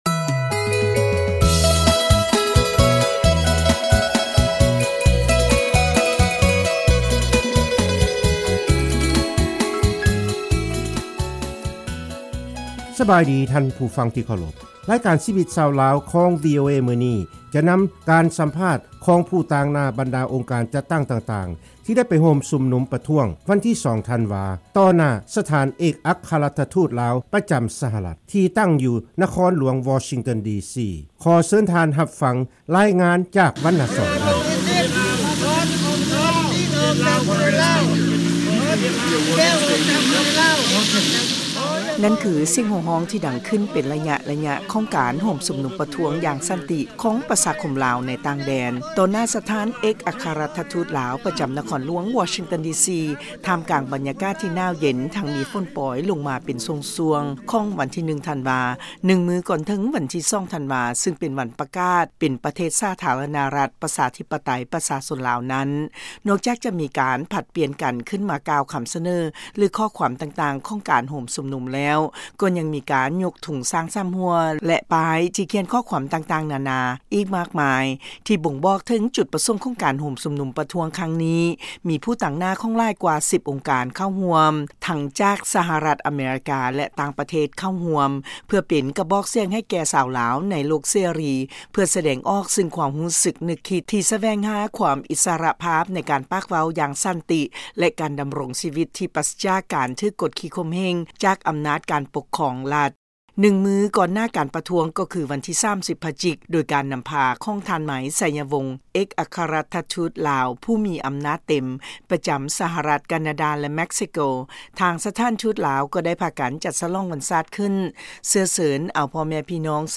ນັ້ນຄືສຽງໂຮງຮ້ອງທີ່ດັງຂຶ້ນ ເປັນໄລຍະໆ ຂອງການໂຮມຊຸມນຸມປະທ້ວງຢ່າງສັນຕິ